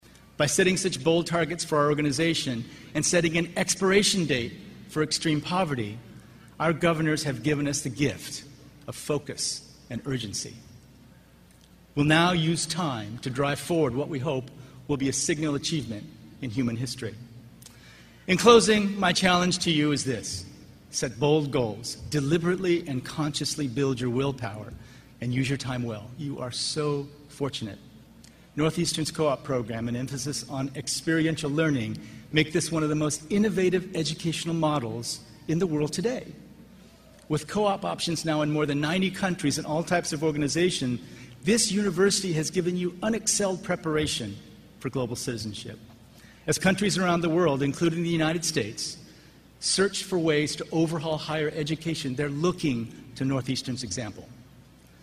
公众人物毕业演讲 第73期:金墉美国东北大学(12) 听力文件下载—在线英语听力室